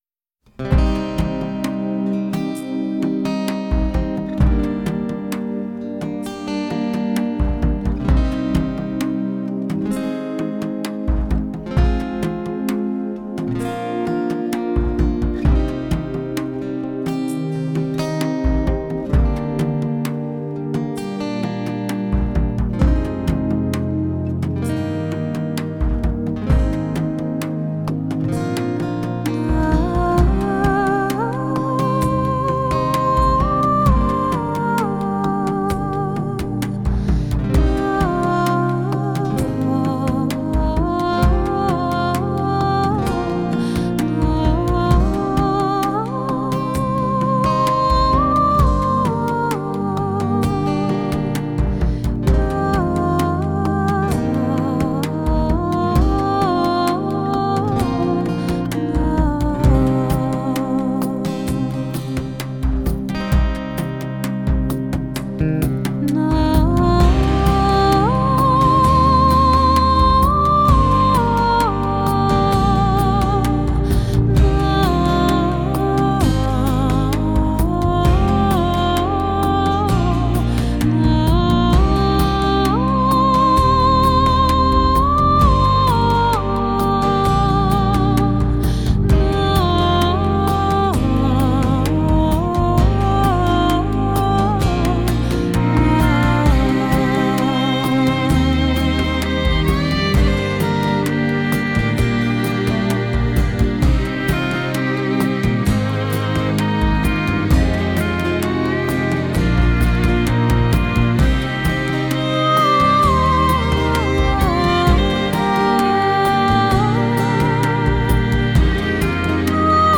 这张声音就是穿插在电影配乐中带有浓浓Celtic味的轻柔唱吟